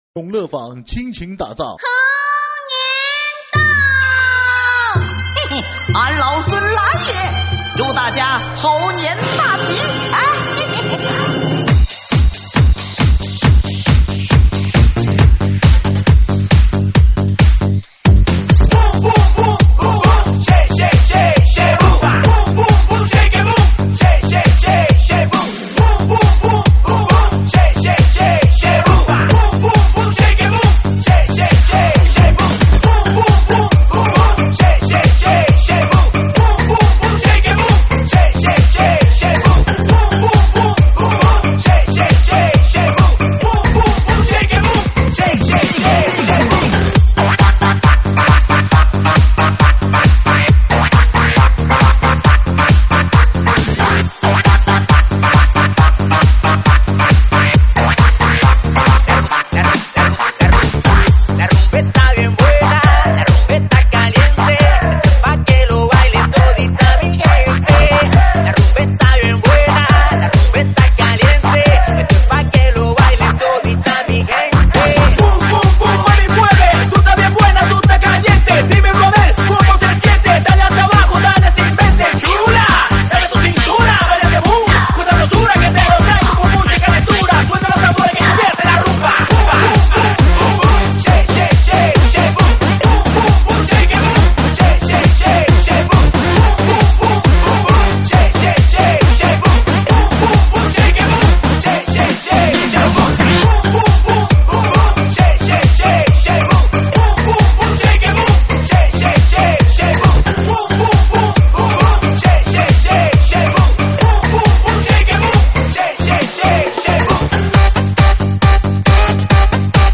新年喜庆